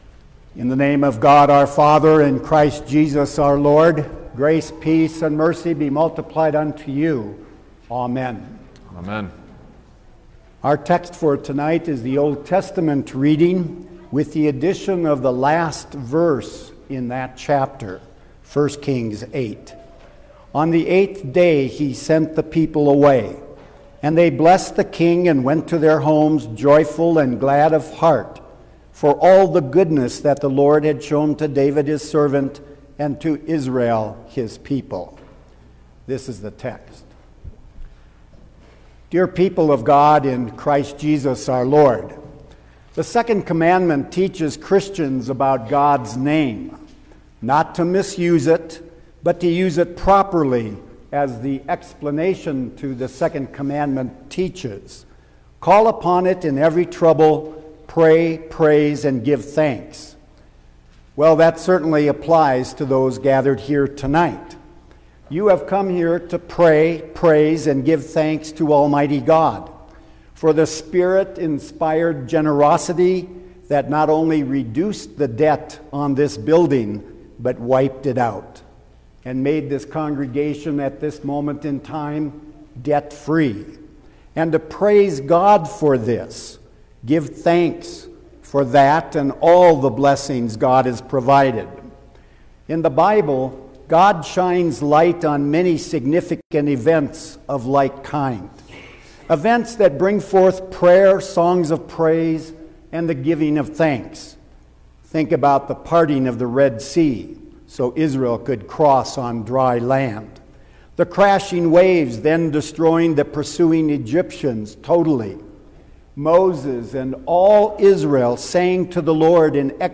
March-5_2023_Debt-Retirement-Service_Sermon-Stereo.mp3